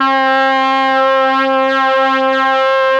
RED.BRASS 18.wav